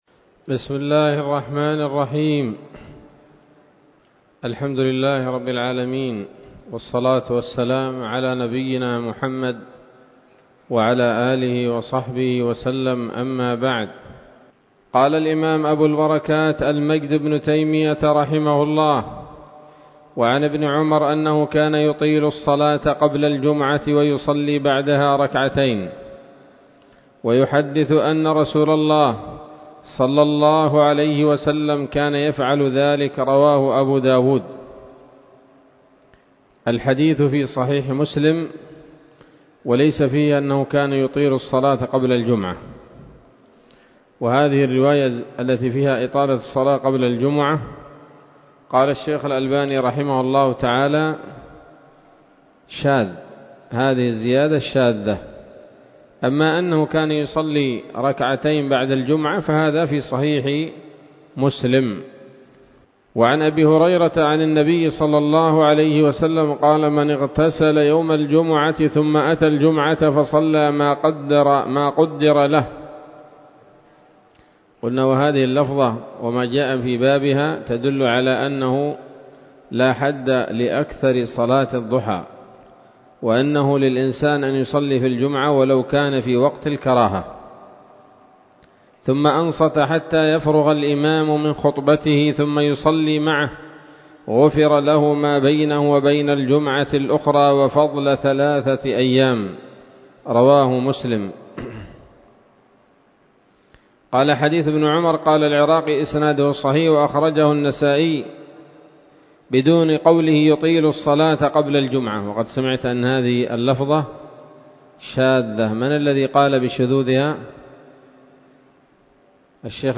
الدرس الحادي والعشرون من ‌‌‌‌أَبْوَاب الجمعة من نيل الأوطار